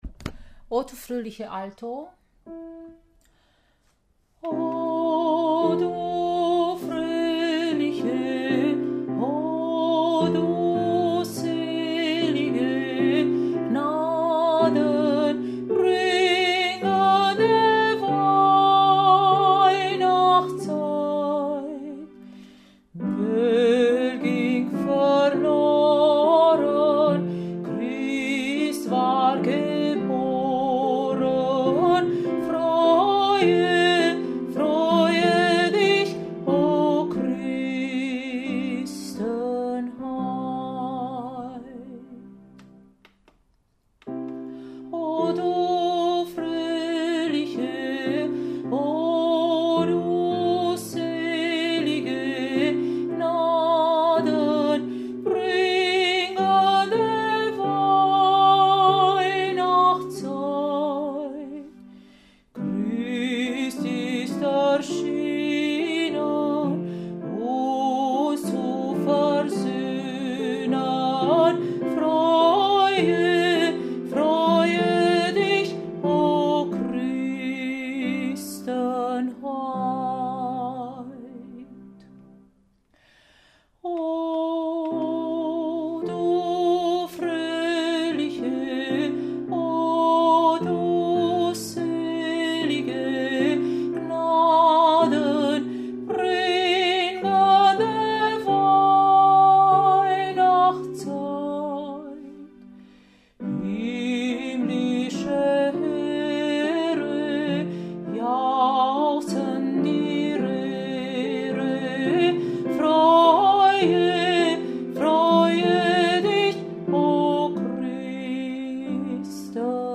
Oh du Fröhliche Alto
Oh-Du-Fröhliche-Alto.mp3